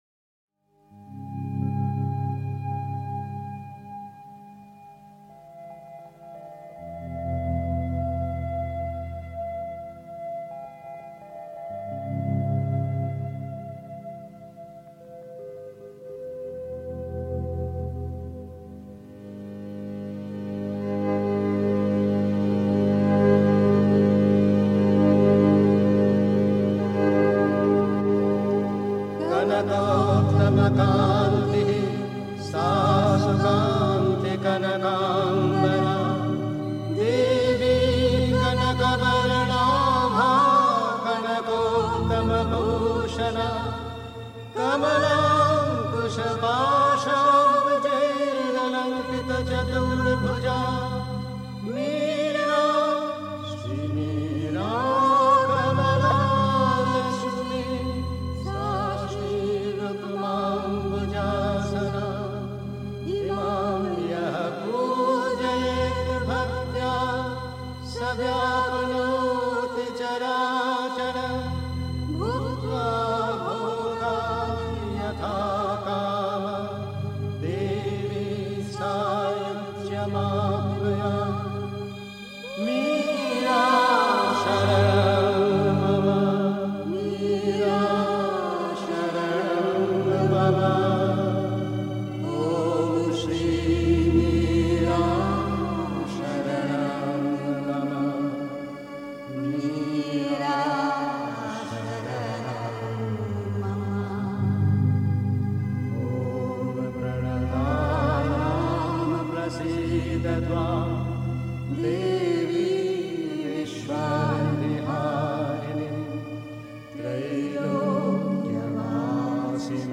Pondicherry. 2. Durch Schwierigkeiten solltest du dich nicht entmutigen lassen (Die Mutter, Weisse Rosen, 20. März 1959) 3. Zwölf Minuten Stille.